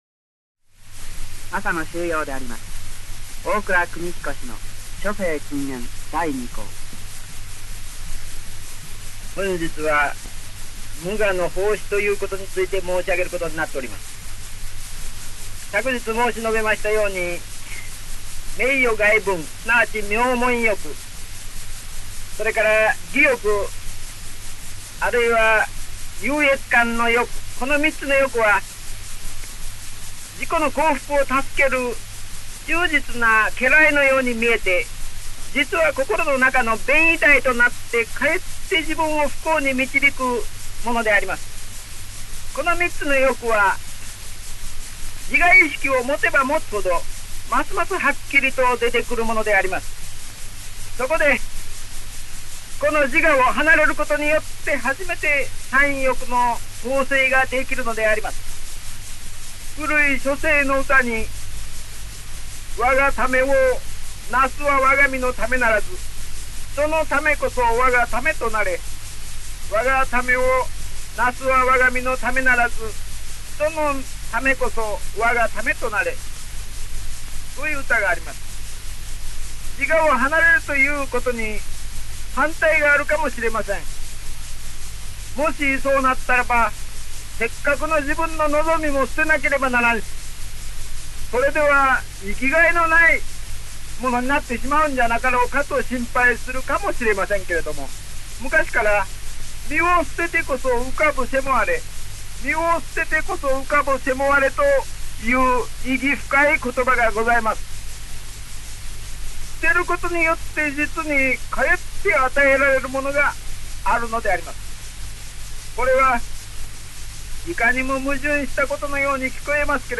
本音声は、その第２回目（昭和12年3月26日）のラジオ放送を録音したSP盤レコードをデジタル化したものです。